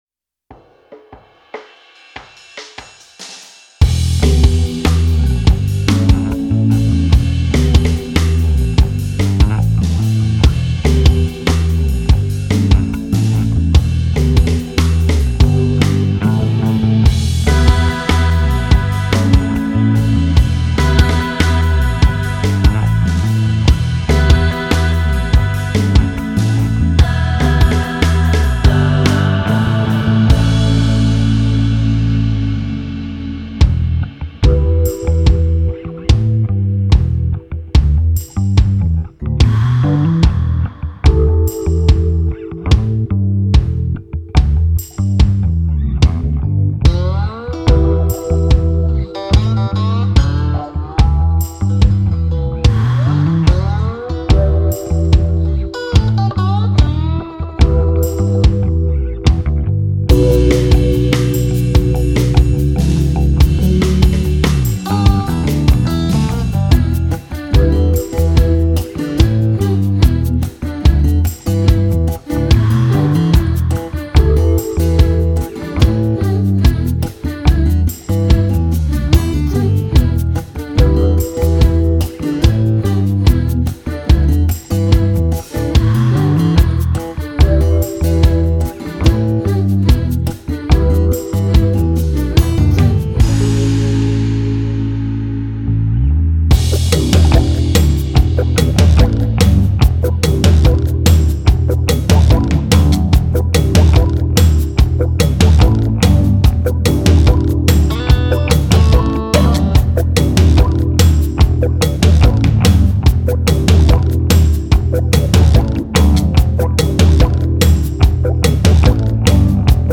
Гитара
гитара, бас